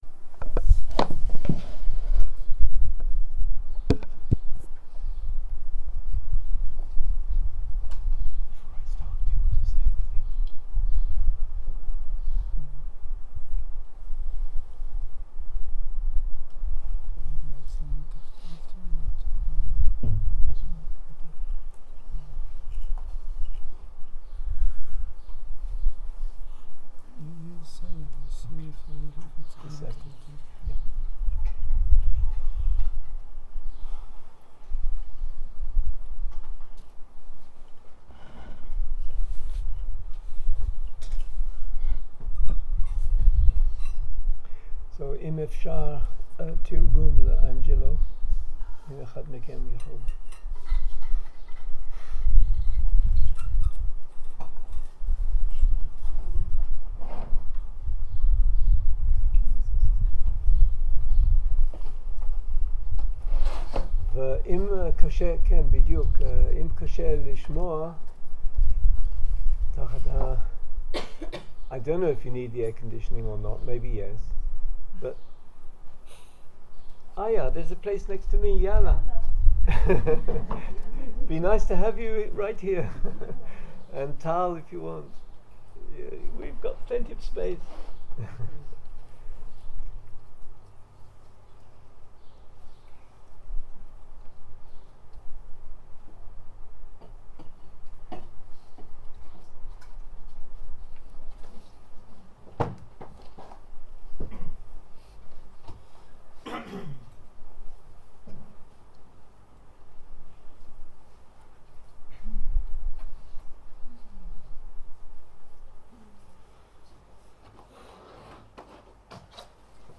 יום 3 -בוקר - שיחת דהרמה - Motivation and energy in practice and teaching הקלטה 3 0:00 0:00 סוג ההקלטה: סוג ההקלטה: שיחות דהרמה שפת ההקלטה: שפת ההקלטה: אנגלית